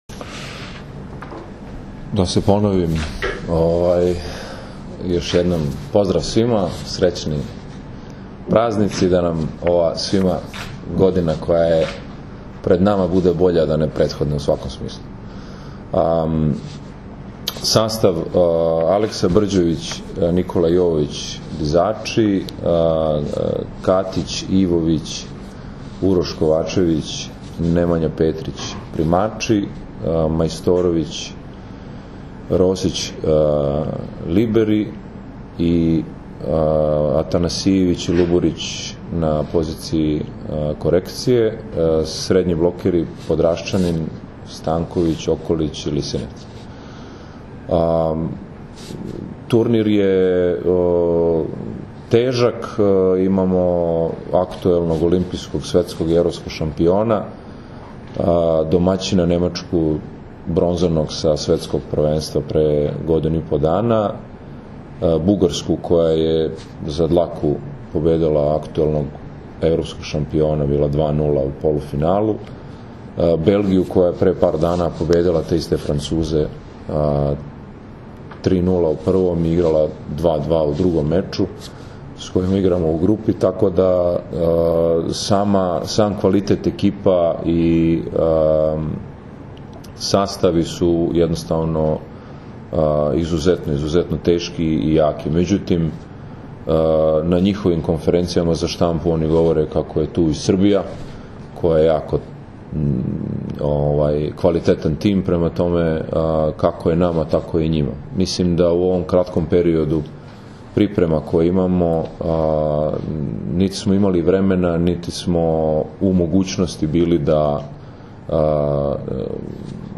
Tim povodom danas je u beogradskom hotelu “M” održana konferencija za novinare, kojoj su prisustvovali Nikola Grbić, Dragan Stanković, Marko Ivović i Uroš Kovačević.
IZJAVA NIKOLE GRBIĆA